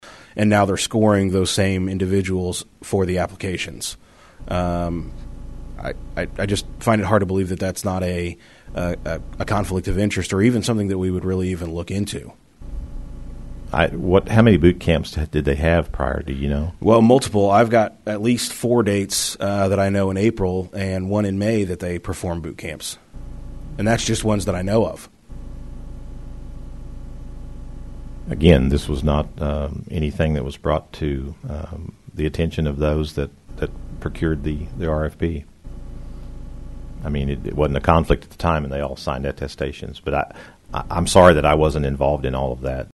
Missouri House panel questions medical marijuana program’s choices, calls on RFP agency to testify (AUDIO FROM HEARING)
The House Committee on Government Oversight member Jered Taylor, R-Republic, asked medical marijuana program director Lyndall Fraker if he knew that partners in the chosen scoring company had in the past been hired for training by potential applicants:
Taylor-asks-Fraker-about-Oaksterdam.mp3